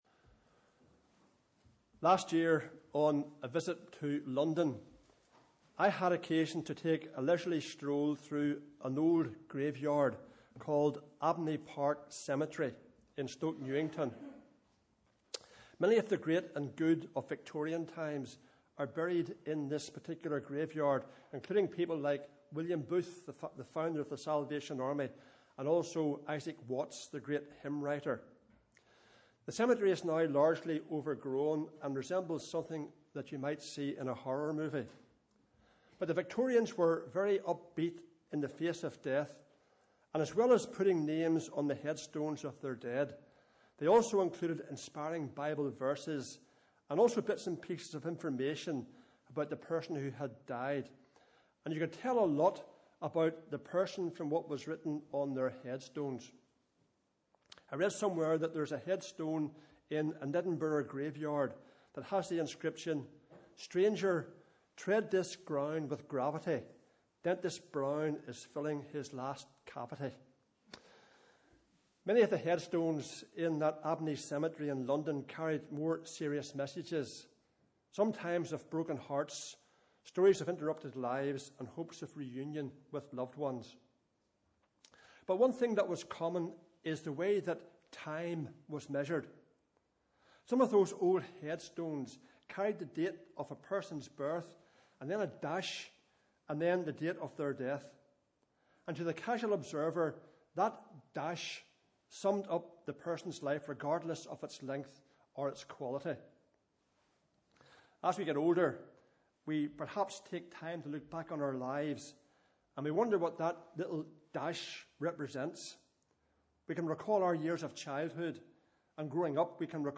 Sunday 5th July Morning Service @ 11:00am Bible Reading: 2 Timothy 4